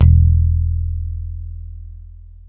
Index of /90_sSampleCDs/Roland L-CDX-01/BS _E.Bass 2/BS _Rock Bass